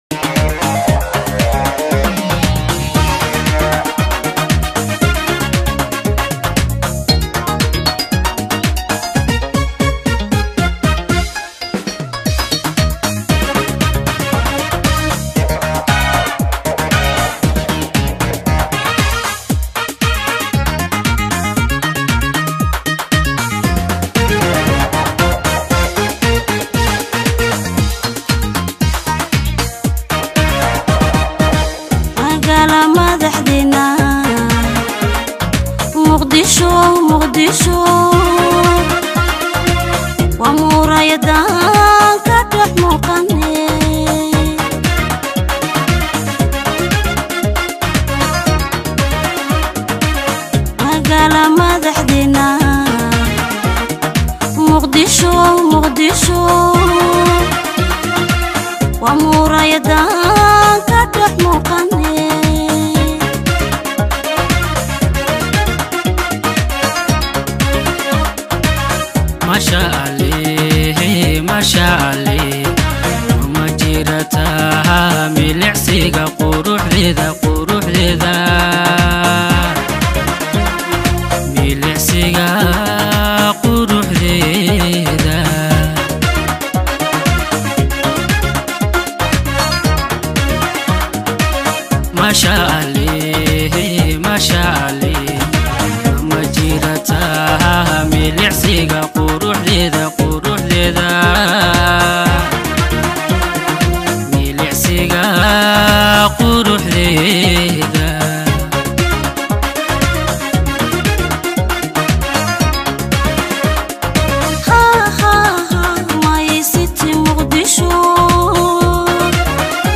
Hadaba waa kuma madaxweynaha cususb ee Somaliland, Muuse Biixi Cabdi, isaga laftigiisa ayaa taariikhdiisa oo kooban